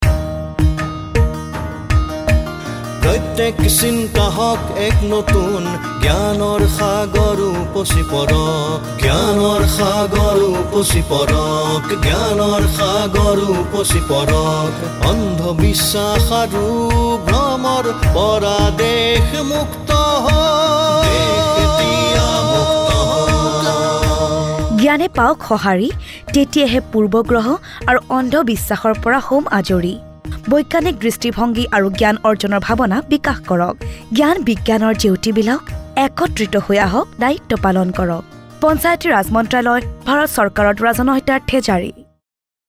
32 Fundamental Duty 8th Fundamental Duty Develop scientific temper Radio Jingle Assamese